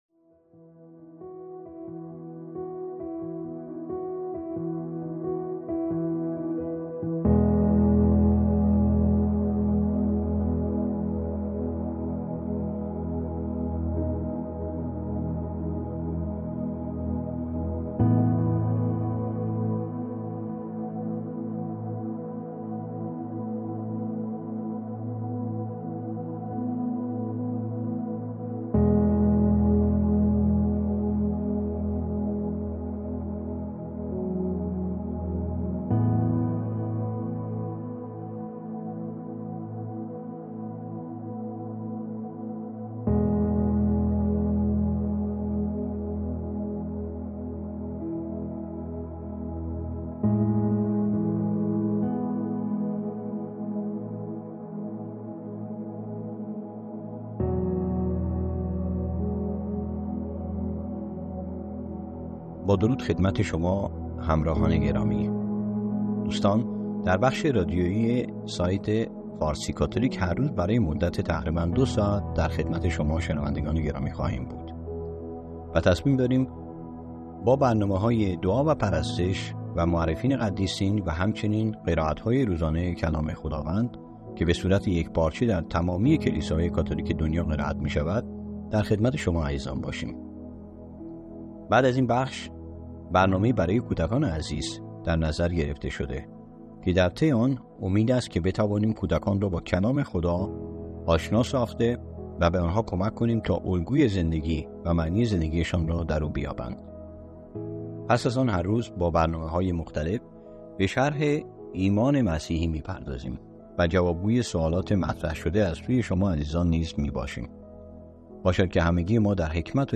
حدود دو ساعت برنامه های صوتی روزانه فارسی کاتولیک به شکل فایل، شامل انواع دعا،قرائت های کلیسایی و... برای شما عزیزان آماده گشته است
سرودهای پرستشی الهام‌بخش